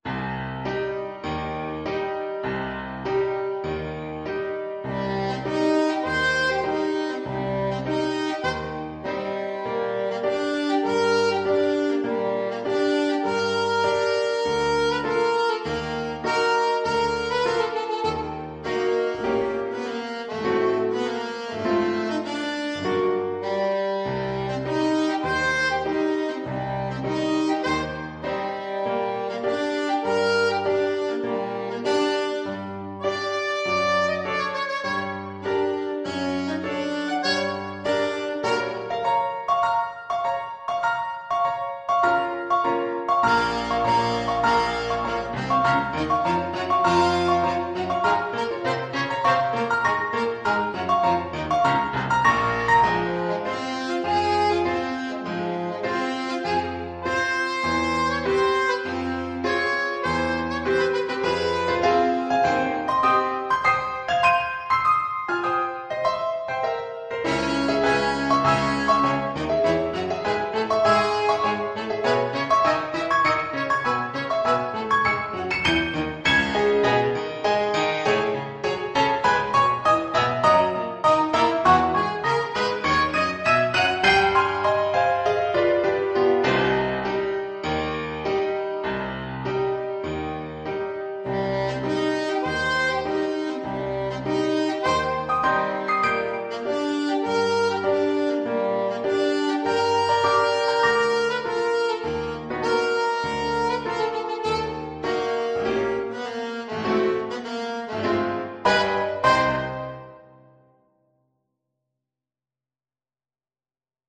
Alto Saxophone
4/4 (View more 4/4 Music)
Classical (View more Classical Saxophone Music)